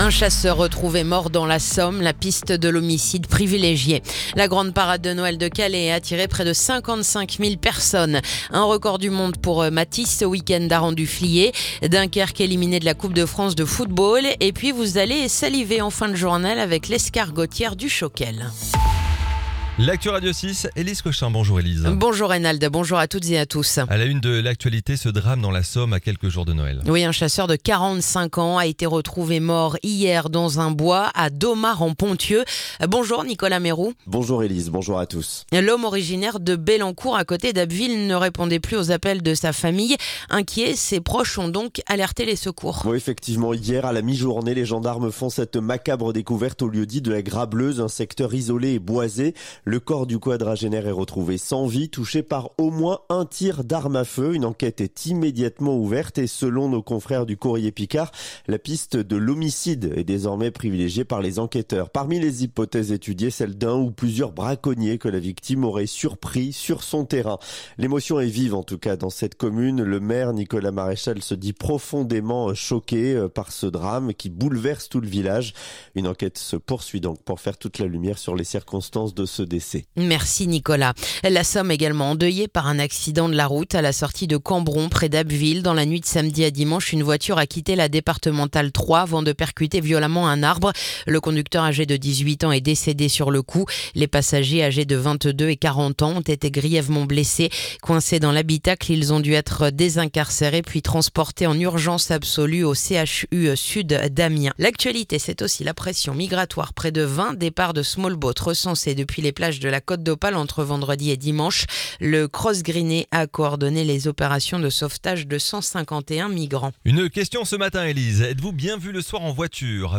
Le journal du lundi 22 décembre